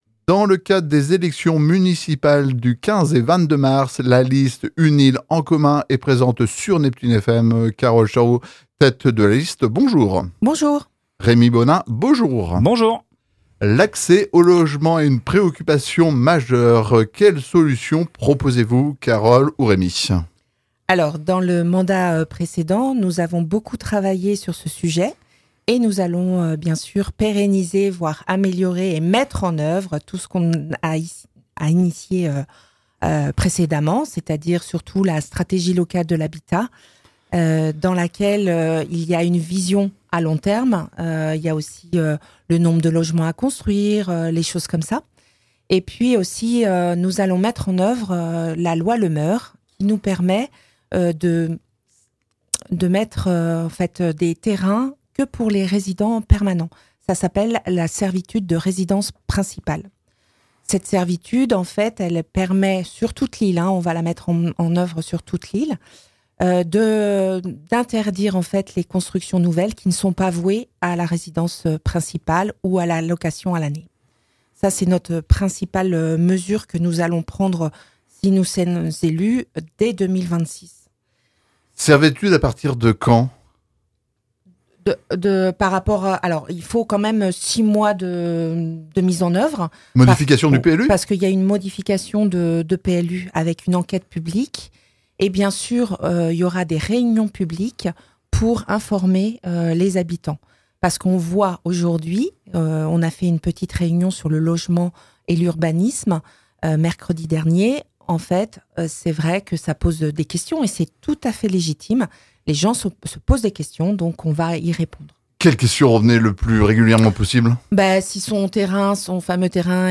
L’ordre de passage des candidats a été défini par ordre alphabétique de leur nom de famille. Cette émission a été enregistrée le lundi 23 février 2026 dans les conditions du direct, aucun montage n'a été effectué dans les propos des candidats.